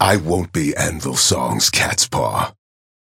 Warden voice line - I won't be Anvil's song's cat's paw.